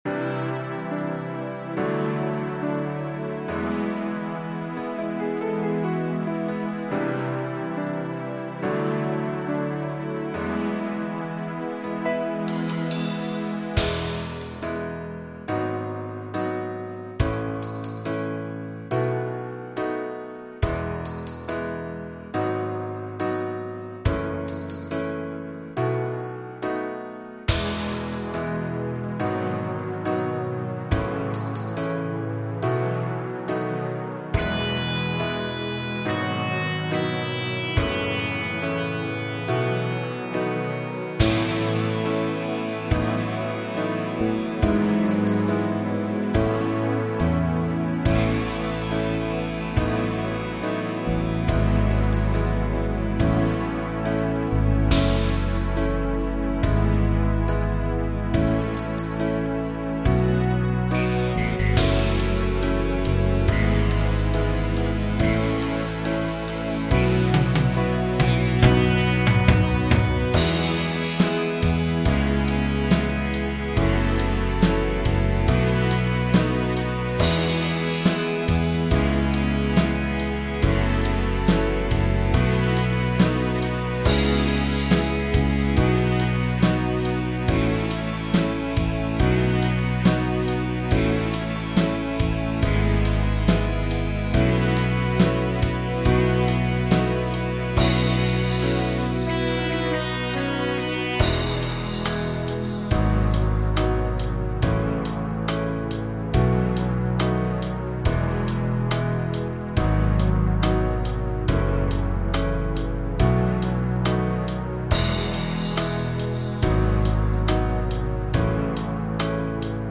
Runterladen (Mit rechter Maustaste anklicken, Menübefehl auswählen)   Neue Brücken (Playback)
Neue_Bruecken__4_Playback.mp3